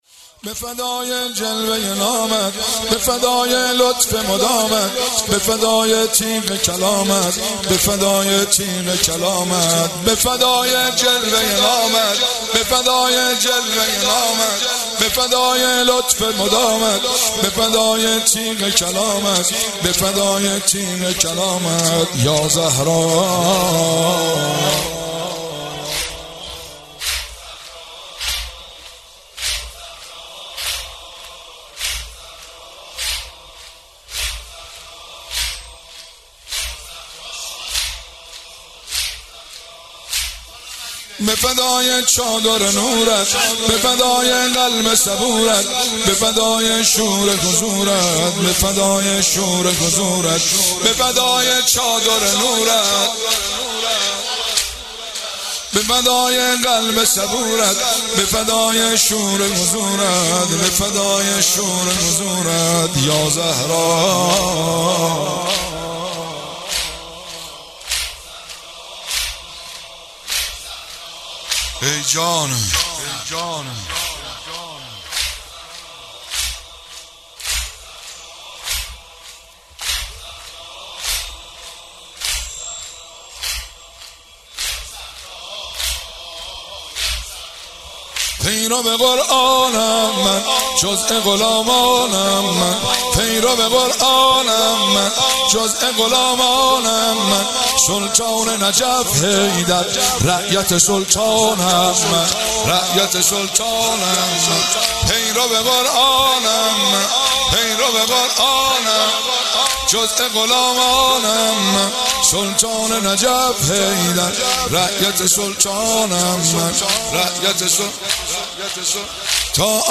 ایام فاطمیه۹۷ هیئت فاطمیون قم